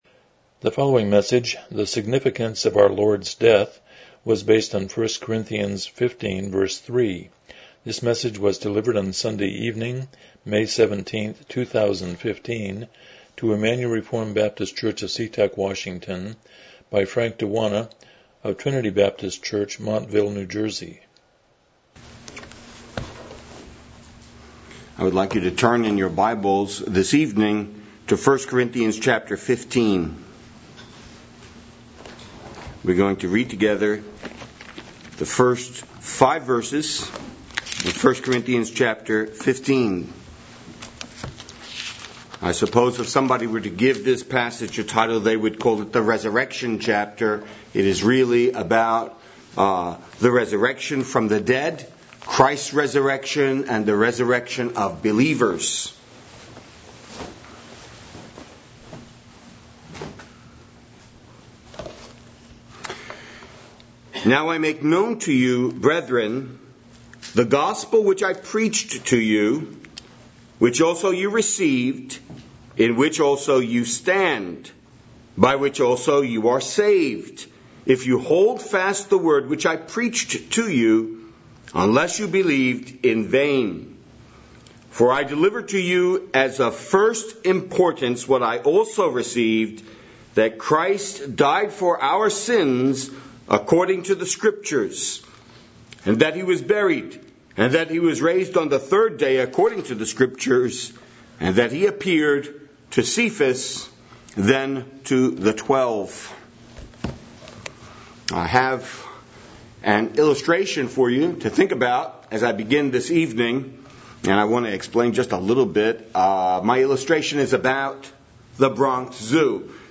Miscellaneous Passage: 1 Corinthians 15:3 Service Type: Evening Worship « The Excellence of Christ as Savior Fighting the Good Fight